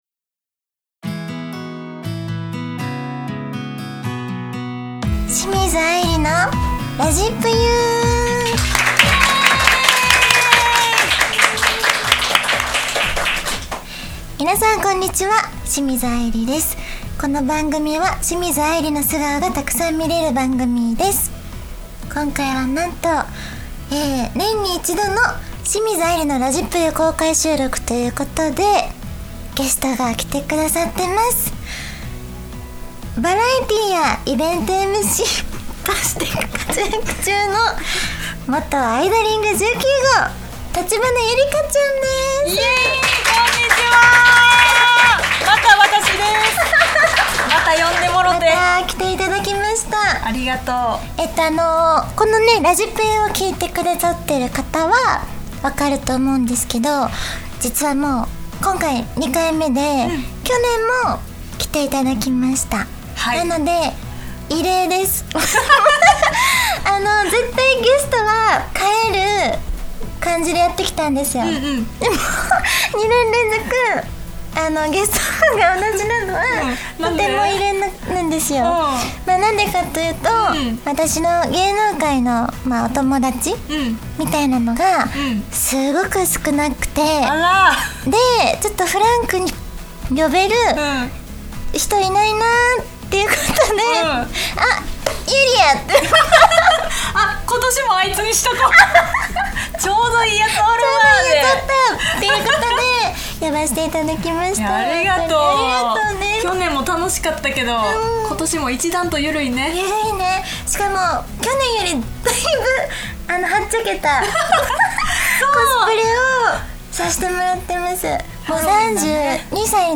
今年も公開収録の季節がやってきました！